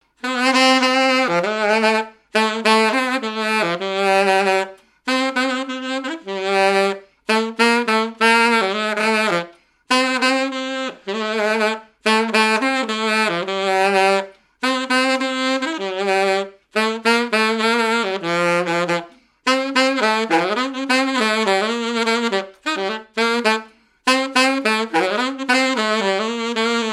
Mémoires et Patrimoines vivants - RaddO est une base de données d'archives iconographiques et sonores.
danse : quadrille : avant-quatre
activités et répertoire d'un musicien de noces et de bals
Pièce musicale inédite